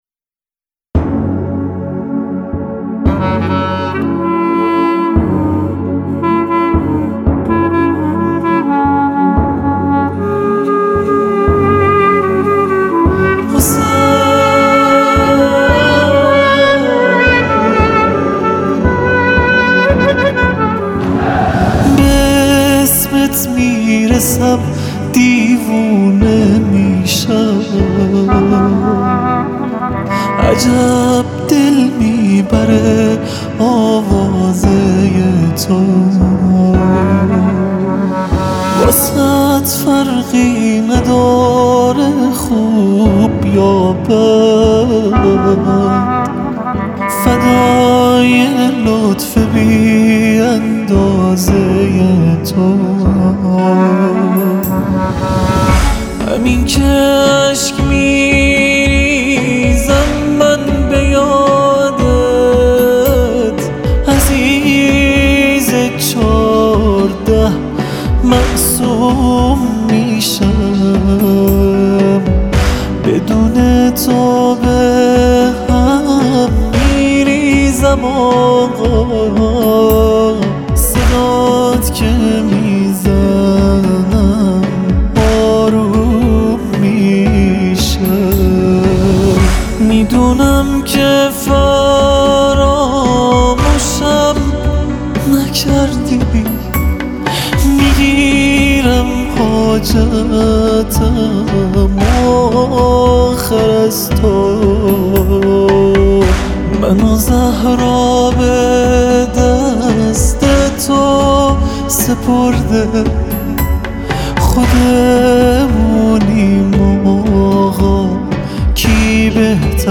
مذهبی و نوحه